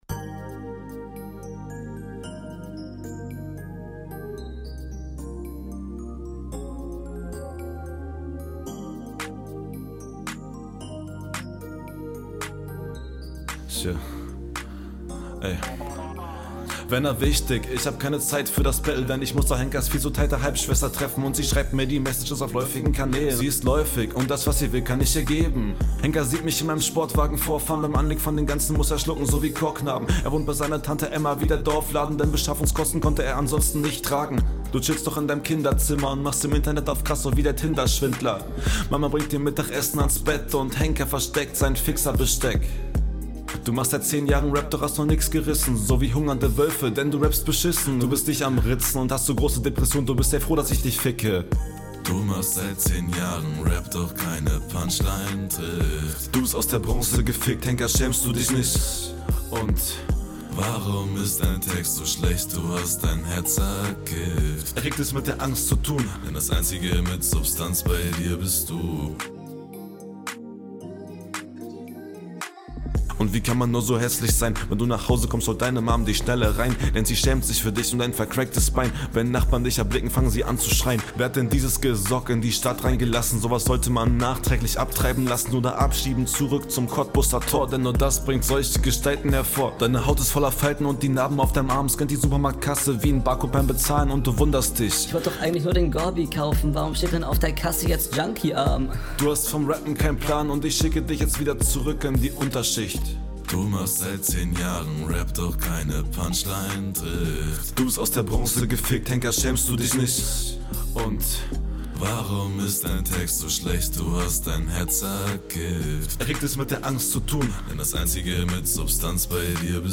Flow zu gehetzt.